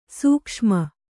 ♪ sūkṣma